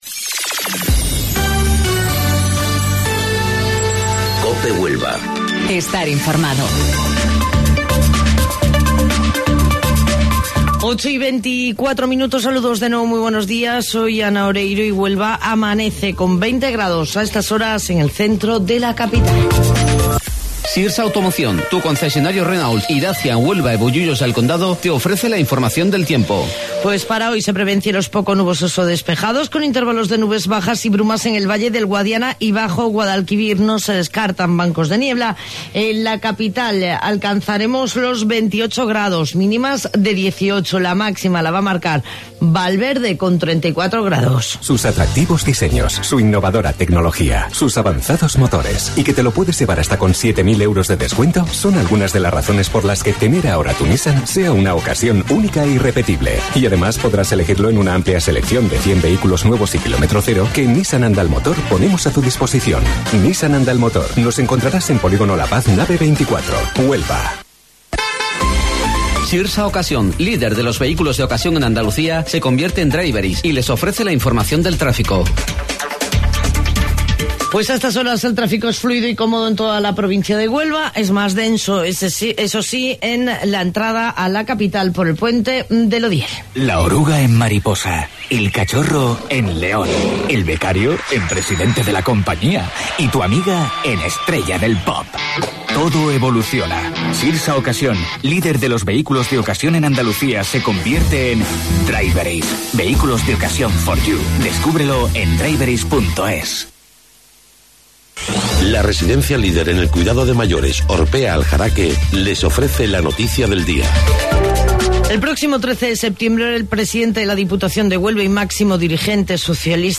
AUDIO: Informativo Local 08:25 del 17 de Julio